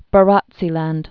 (bə-rŏtsē-lănd)